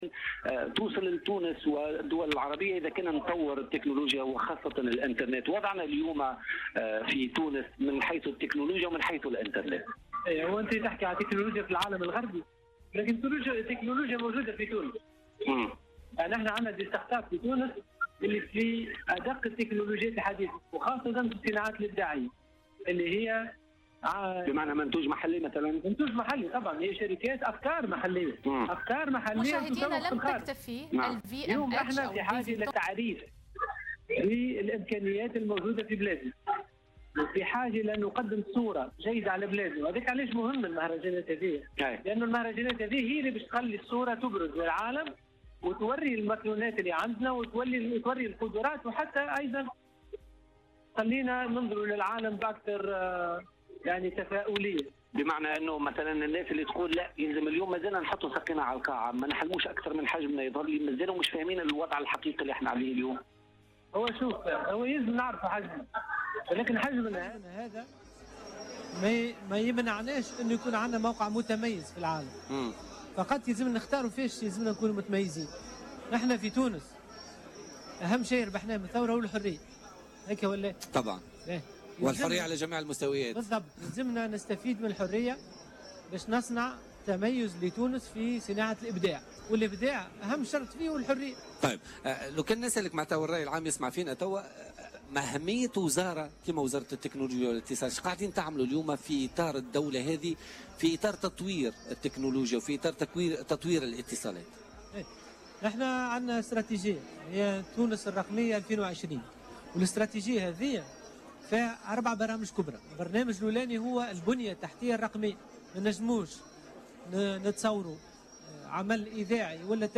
أكد محمد أنور معروف وزير تكنولوجيات الاتصال والاقتصاد الرقمي ضيف بوليتيكا اليوم الأربعاء أن الحكومة لديها استراتيجية هي "تونس الرقمية 2020" وهذه الإستراتيجية تنبني على 4 برامج كبرى على حد قوله.